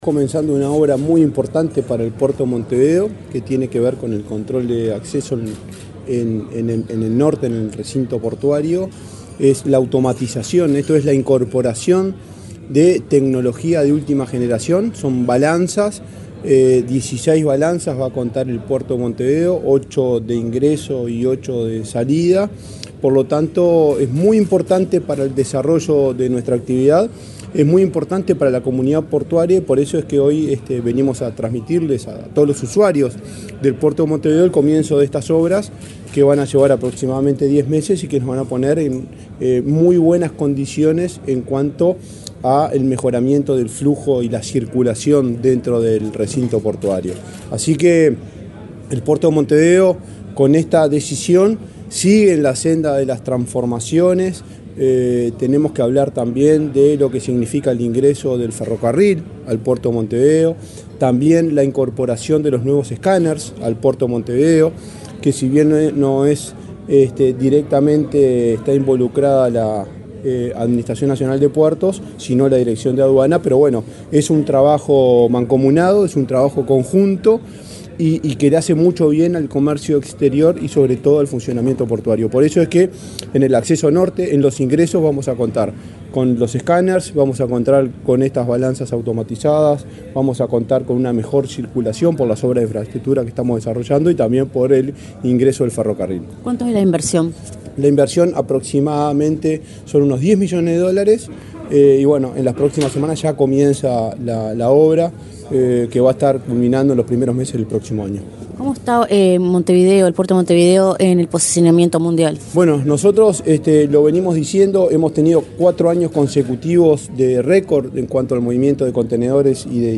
Entrevista al presidente de la ANP, Juan Curbelo